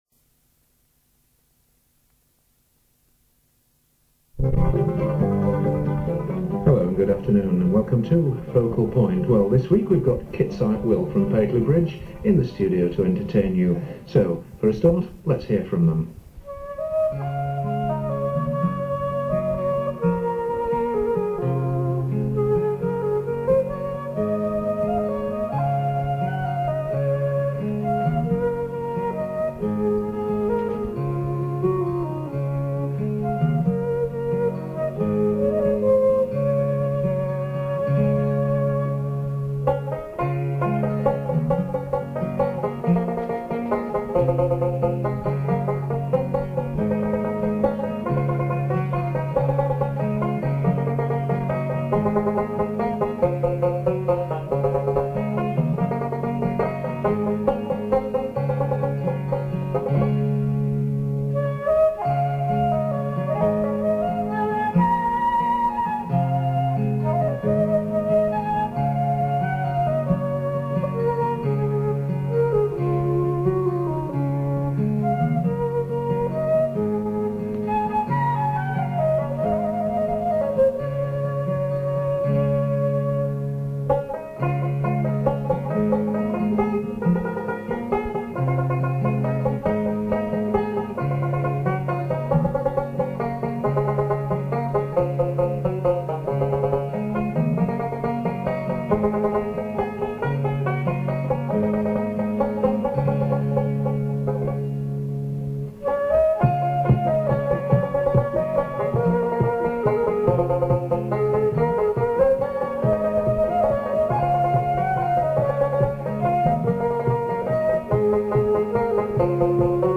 The recording quality was probably never very good and has inevitably deteriorated over the years but still gives an idea of the nature of the material and the approach to its performance.
Flute
Guitar
Bodhran
Tenor Banjo